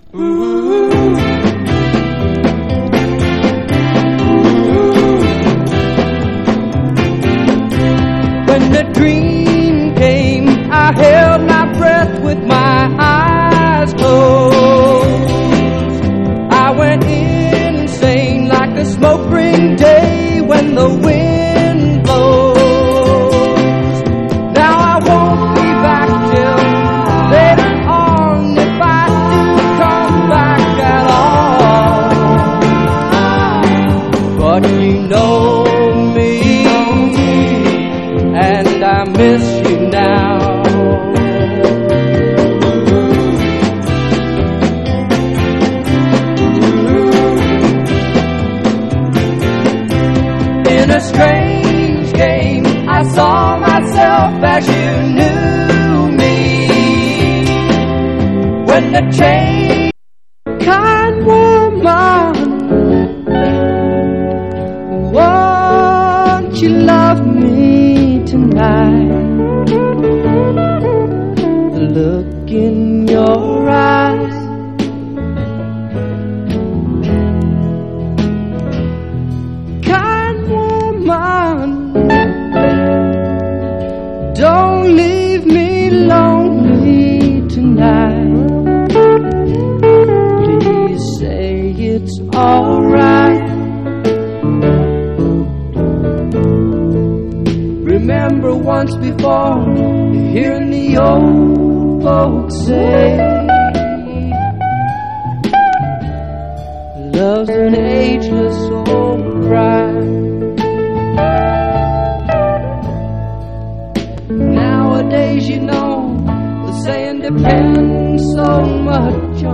NEW WAVE / ELE POP / SYNTH POP
シンセを多用した未来感溢れるサウンドと無機質ヴォーカルが幻想的で斬新な印象を与えた出世作！